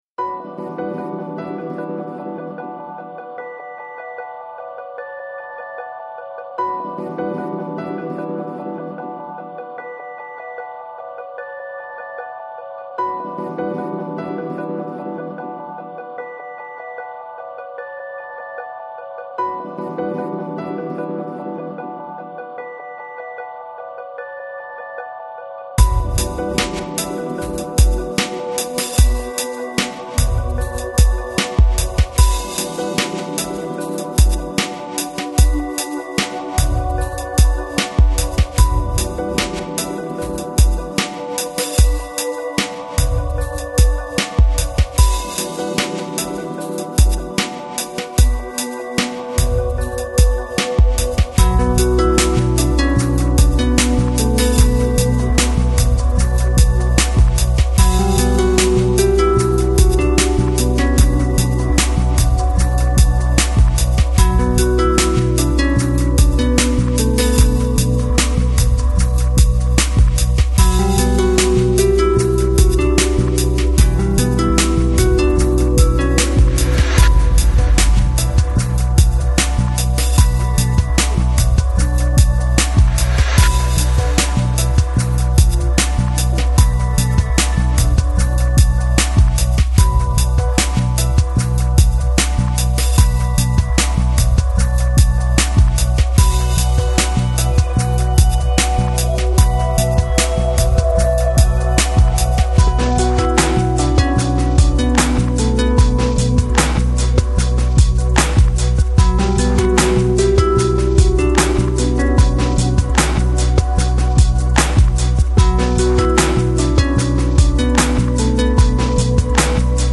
Жанр: Electronic, Lounge, Chill Out, Downtempo, Balearic